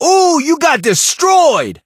brock_kill_vo_02.ogg